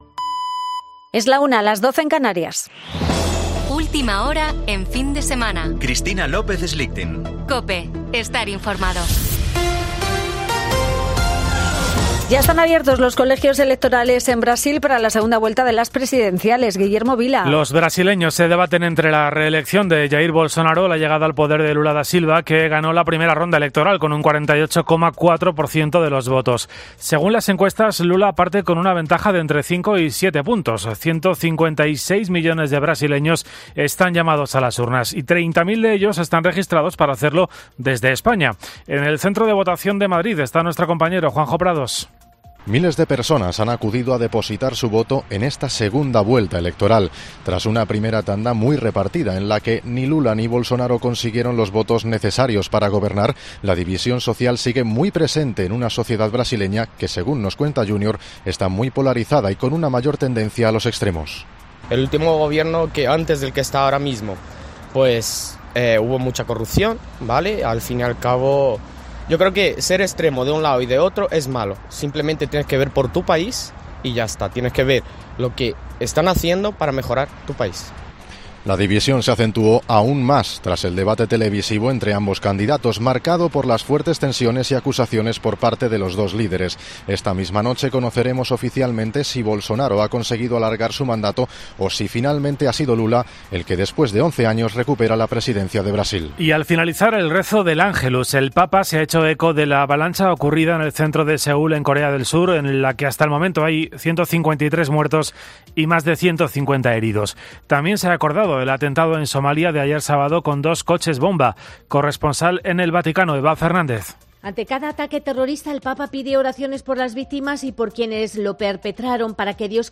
Boletín de noticias de COPE del 30 de octubre de 2022 a las 13.00 horas